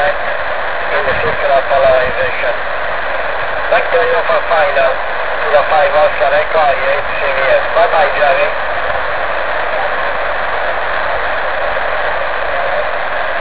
La senyal recibida erade 8 (3 más que con la 16 vueltas), en el c-58
Fragmento de un QSO entre dos estaciones en el momento de la prueba.
Esta prueva està hecha con un conversor de frecuéncia de la firma SSB Electrónics UEK-3000-sat